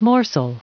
Prononciation du mot morsel en anglais (fichier audio)
Prononciation du mot : morsel